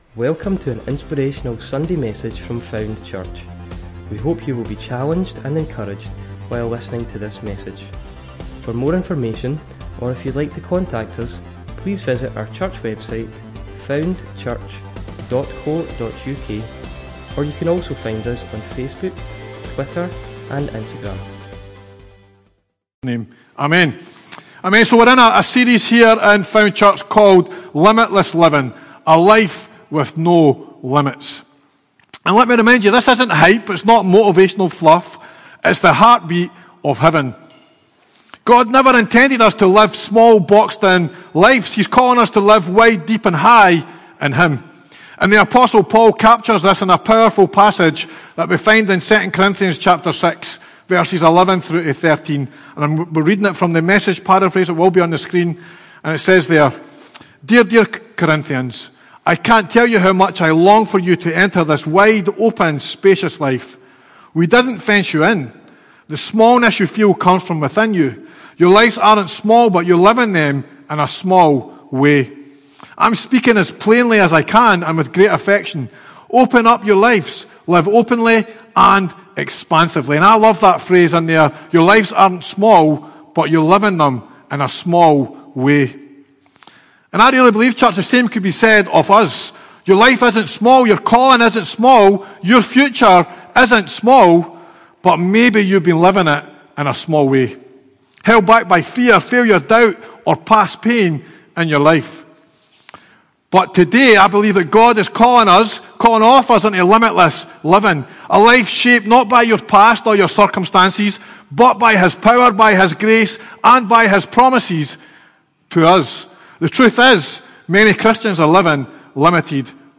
Found Church Sermons